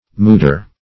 mooder - definition of mooder - synonyms, pronunciation, spelling from Free Dictionary Search Result for " mooder" : The Collaborative International Dictionary of English v.0.48: Mooder \Moo"der\, n. Mother.
mooder.mp3